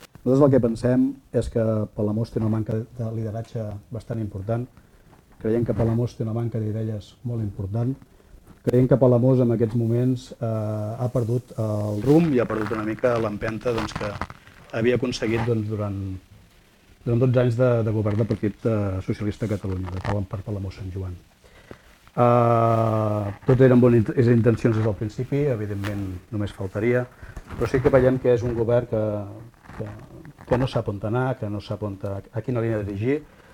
El debat electoral de Palamós i Sant Joan 2019 s’ha emès a Ràdio Capital aquest dijous al vespre amb la presència de tots els alcaldables de la vila palamosina, tret del Partit Popular, que ha declinat la invitació.